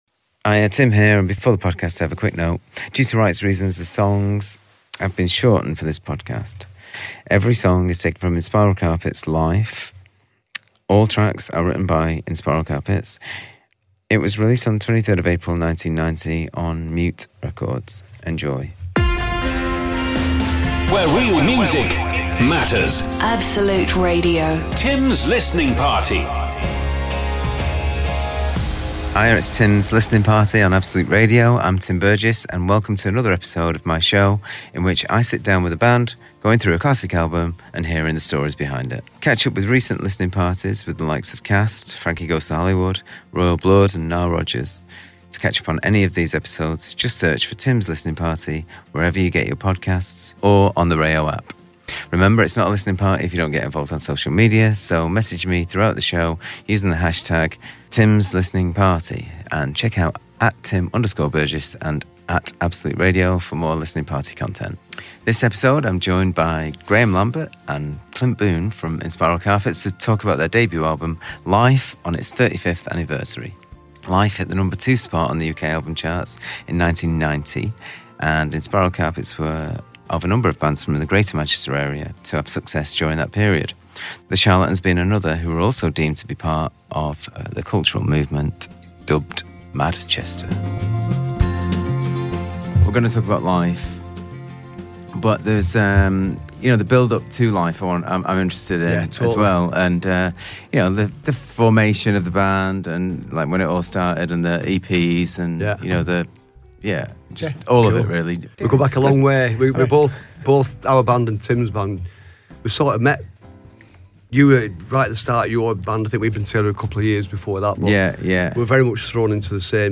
This episode Tim Burgess is joined by Graham Lambert & Clint Boon from Inspiral Carpets to talk about their debut album, Life on it’s 35th anniversary.